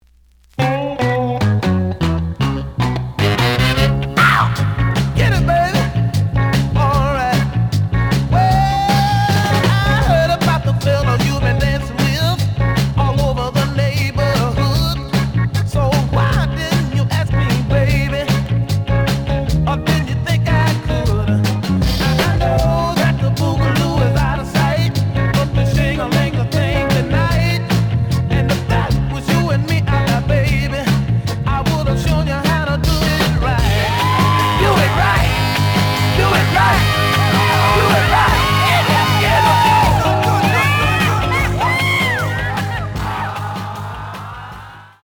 The audio sample is recorded from the actual item.
●Genre: Funk, 60's Funk
Slight noise on beginning of A side, but almost plays good.)